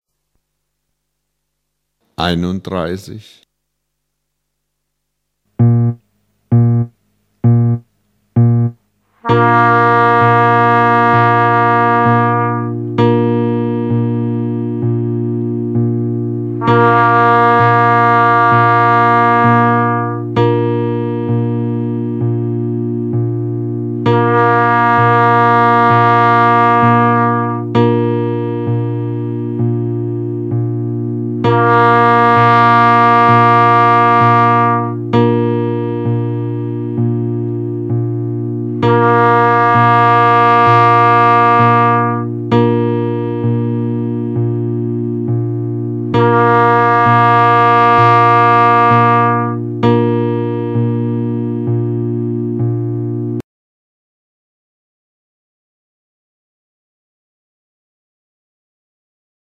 Töne auf dem Horn
Ich spiele die ersten Töne auf dem Signalhorn